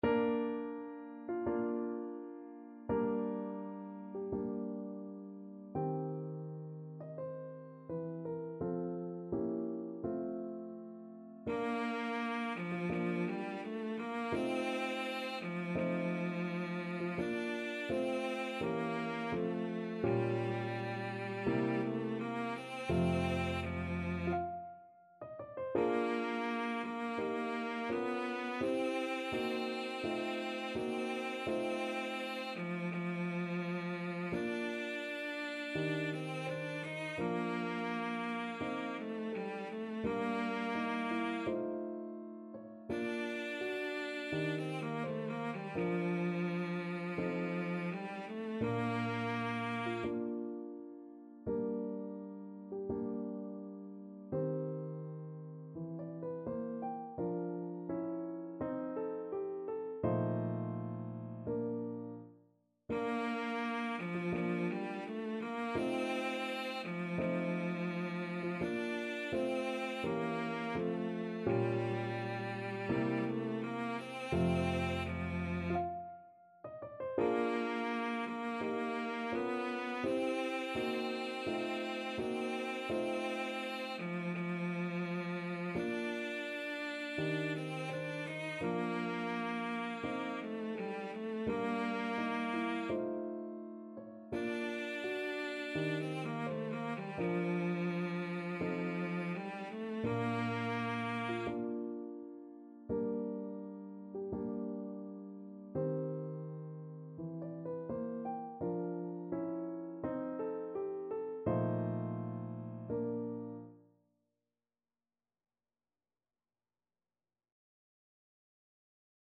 Cello
Bb major (Sounding Pitch) (View more Bb major Music for Cello )
2/4 (View more 2/4 Music)
~ = 42 Sehr langsam
Classical (View more Classical Cello Music)